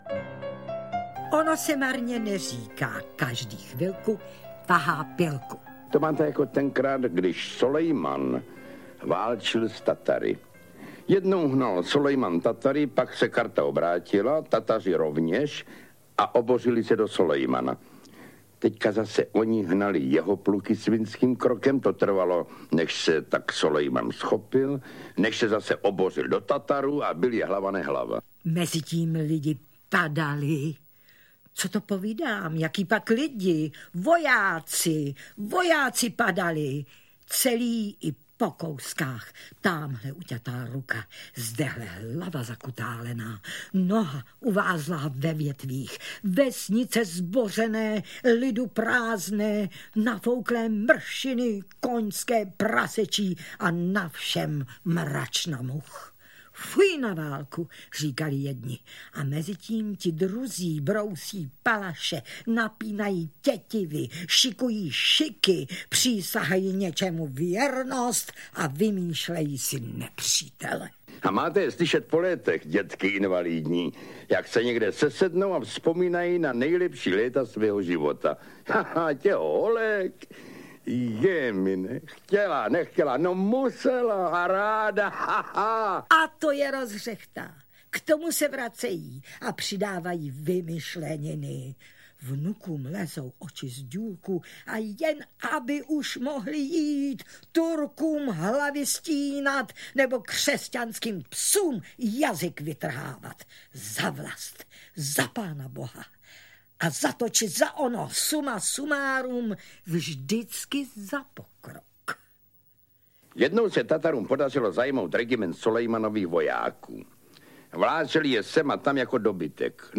Žluté mužátko audiokniha
Vypráví autor Jan Werich a Stella Zázvorková.
Ukázka z knihy
zlute-muzatko-audiokniha